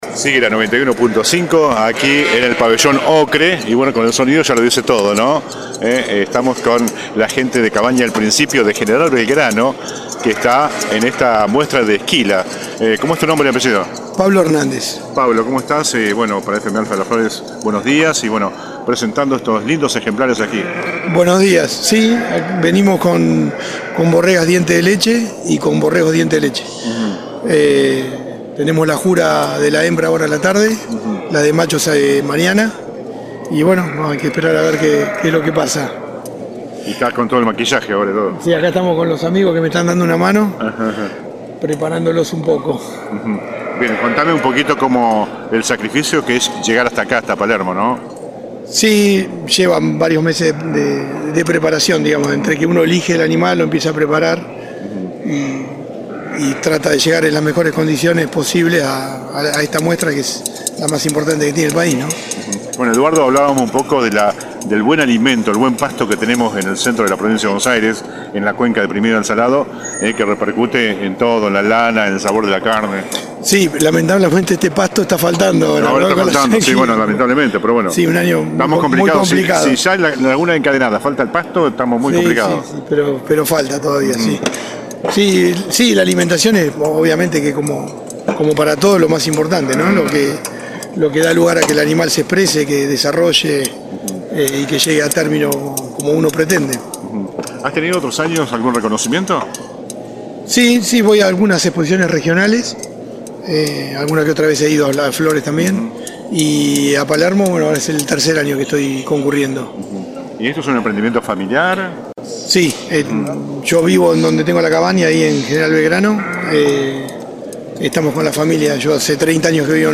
Cabaña «El Principio» de Gral. Belgrano habló con la 91.5 desde Palermo